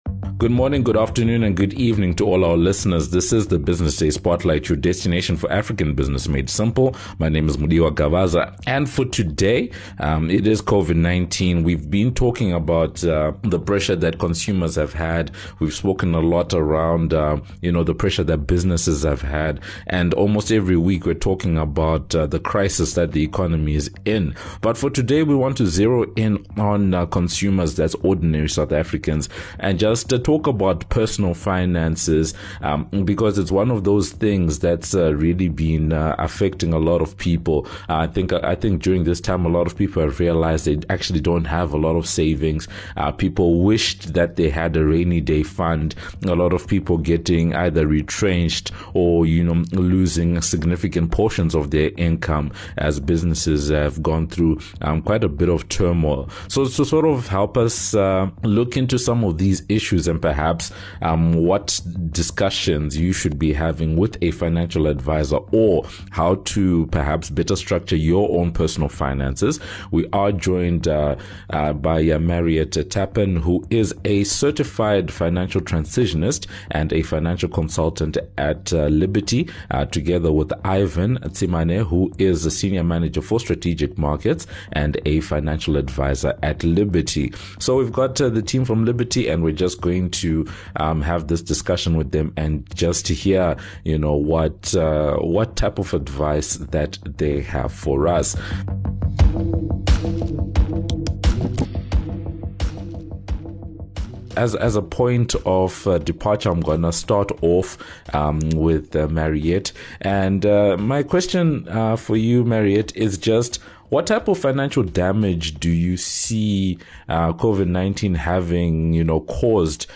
In this edition of the Business Day Spotlight, we’re talking to financial advisors about how to better structure one’s personal during this time of Covid-19.